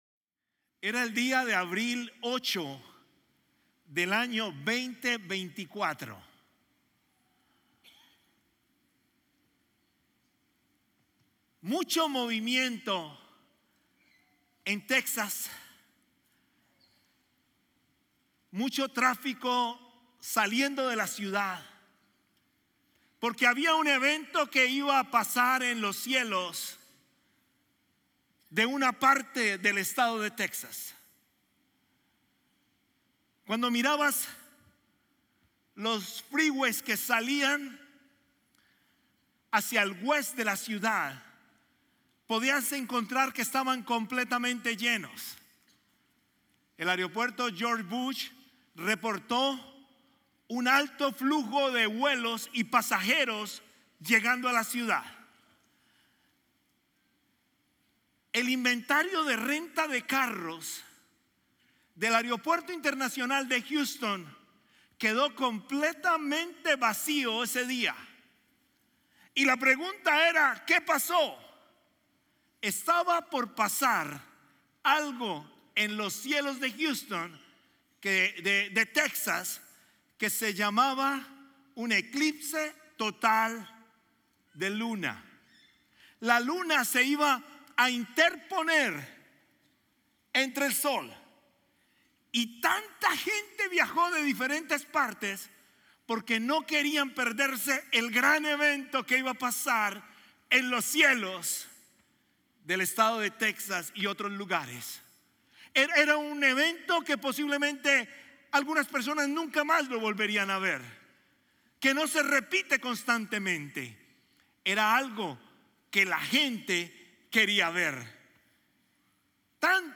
Sermones Dominicales – Media Player